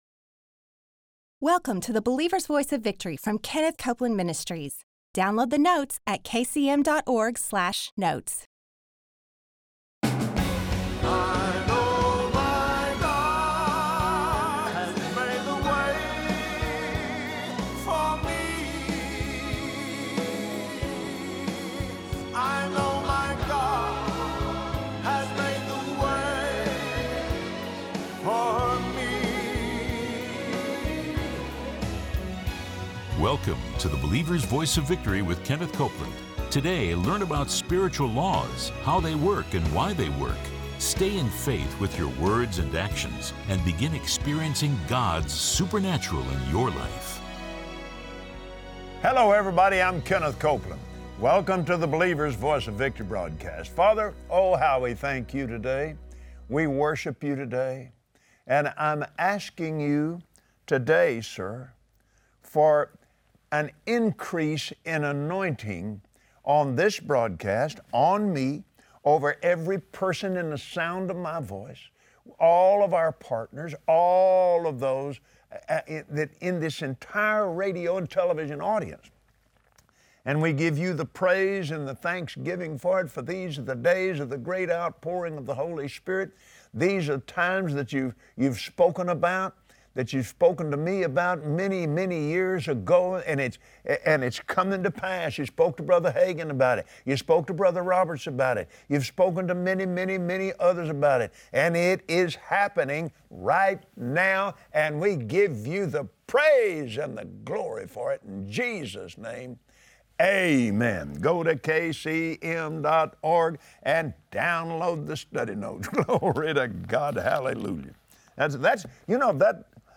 Watch Kenneth Copeland on Believer’s Voice of Victory explain how to activate the anointing power of God by operating in the supernatural realm of faith.